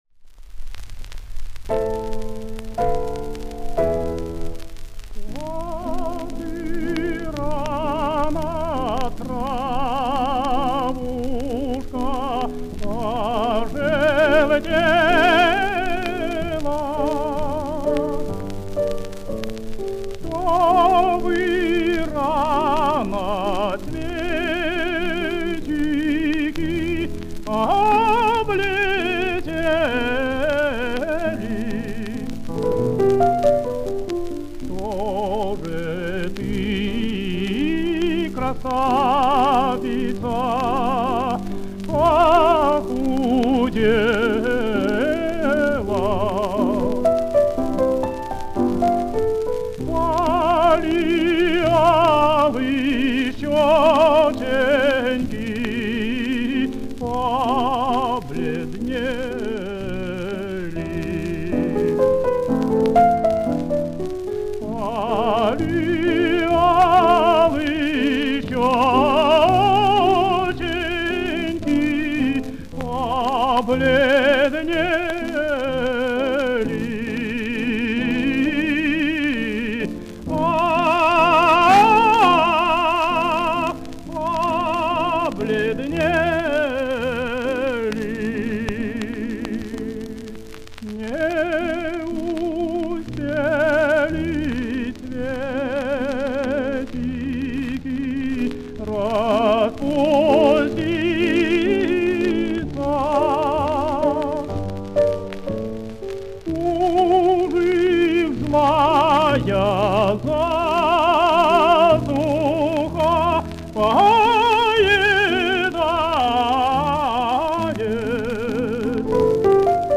тенор
Романс «Что ты рано, травушка».
Партия фортепиано